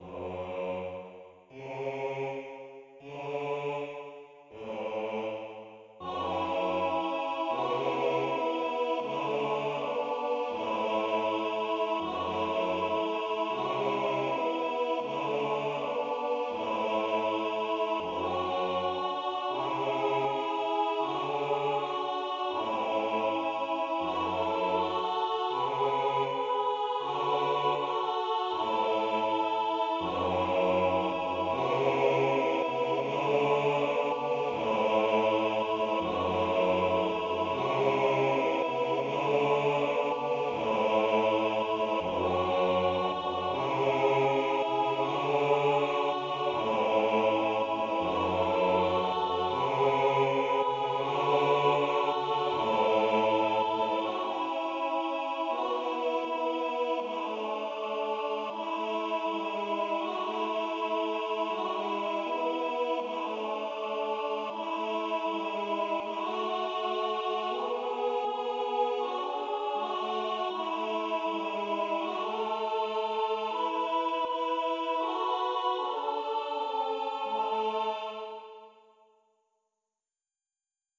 コーラス